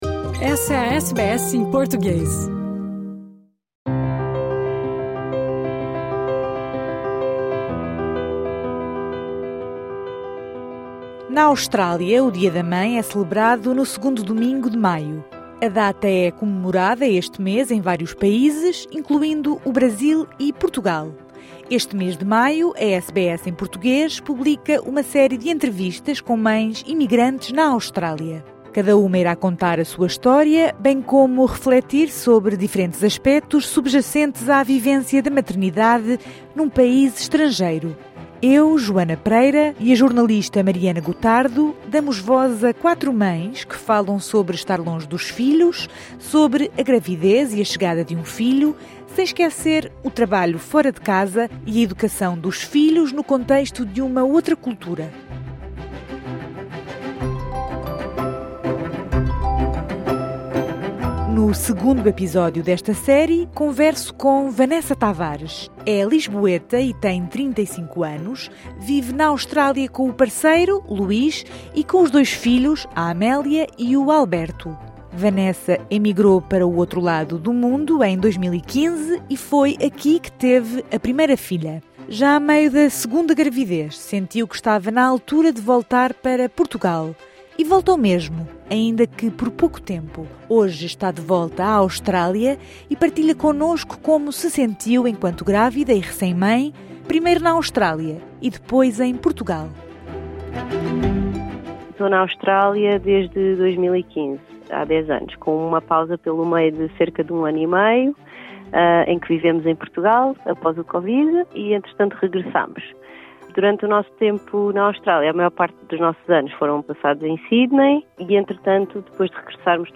Esta entrevista faz parte de uma série que dá voz a mães imigrantes na Austrália.